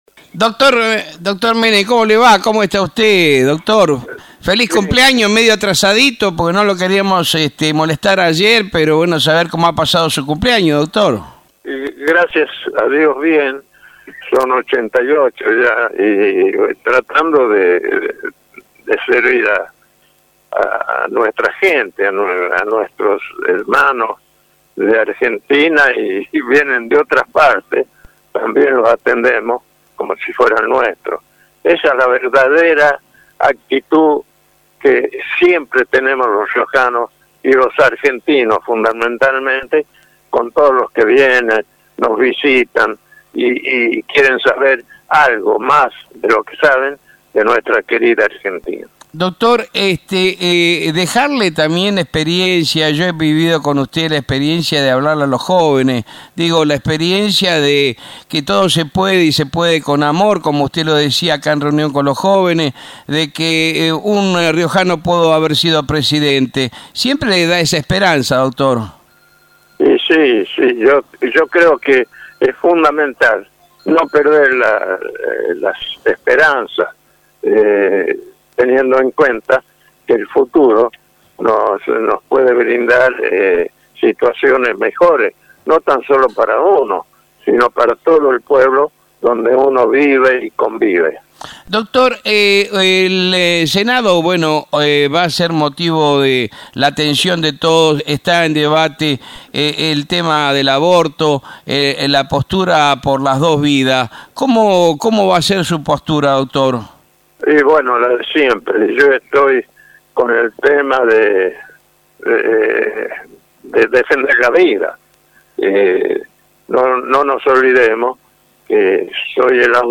Tras cumplir 88 años, Menem recordó por Radio Libertad de La Rioja que impulsó leyes a favor de la vida durante su Presidencia.